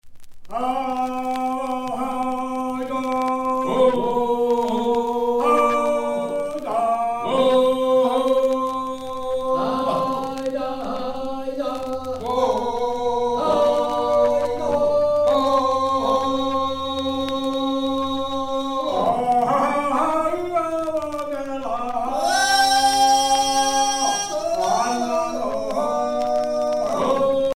Chants de travail